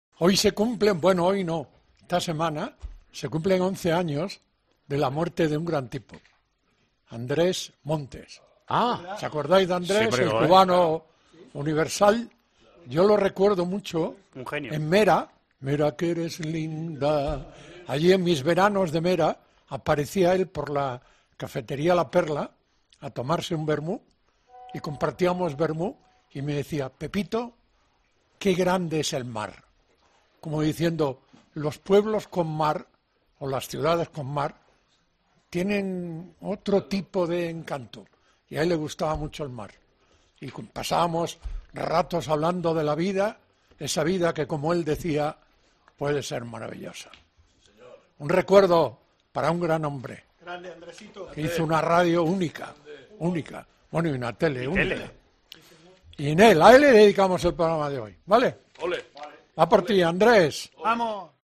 Pepe Domingo Castaño ha rendido homenaje este sábado en Tiempo de Juego a Andrés Montes cuando se cumplen 11 años de su muerte